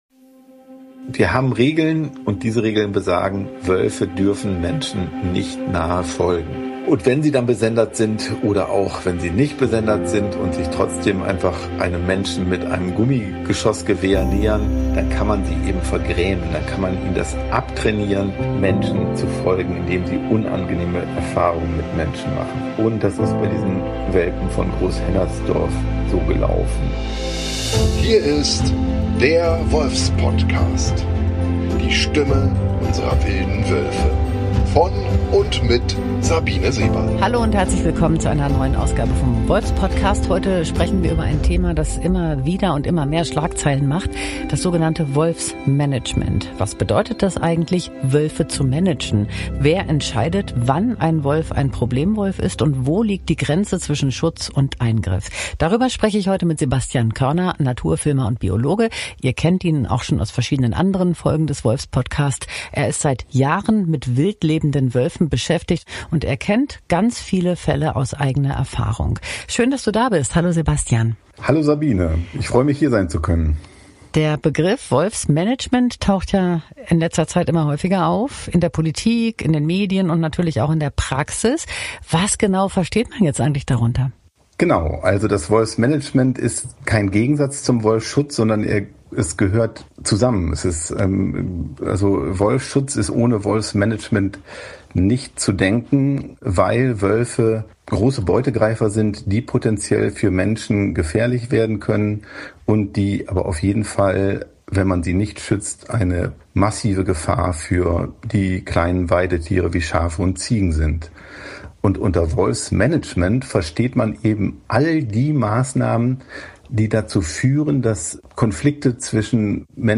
Ein Gespräch über die Biologie unserer Wölfe, Respekt vor der Wissenschaft – und die Frage, warum die Jagdfunktionäre und Regierungspolitiker sich den Fakten so rigoros verweigern.